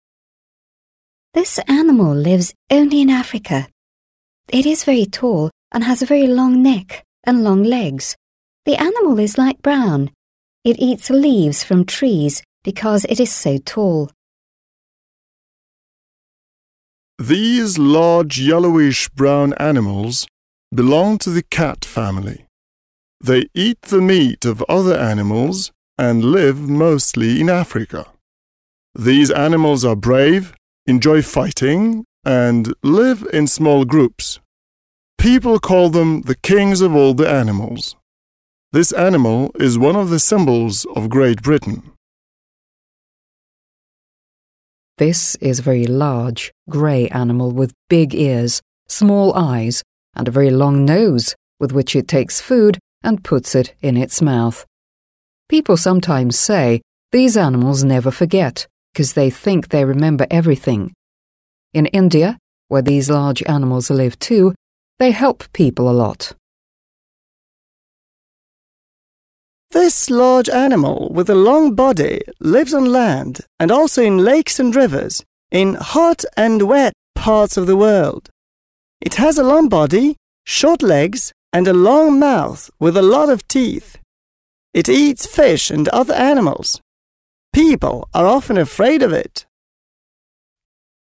Four people speak about the animals that live in Africa.